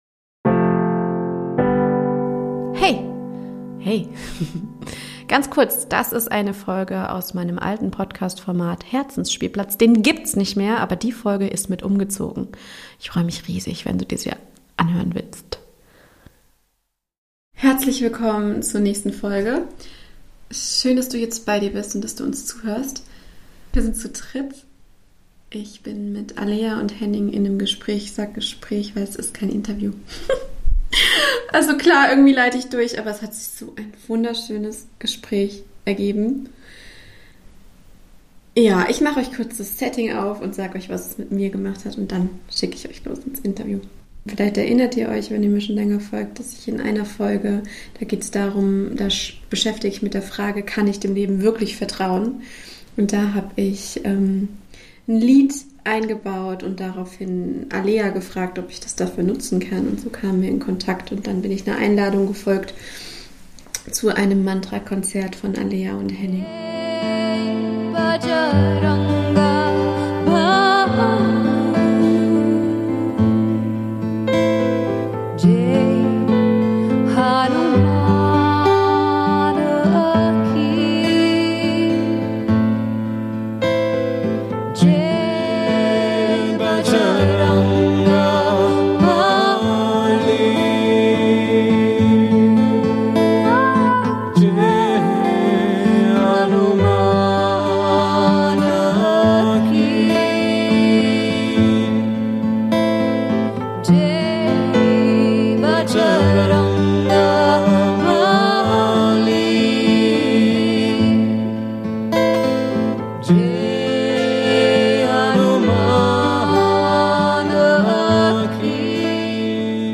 Ich liebe den Raum, den Mantrasingen eröffnet und liebe das Gespräch, was direkt im Anschluss von einem ganz besonderen Mantrakonzert der beiden entstand. Voller Verbundenheit und wunderschön offenen Worten sprechen wir über das Menschsein und Lebendigkeit. Über Balance, Fernab von Balance und alles dazwischen.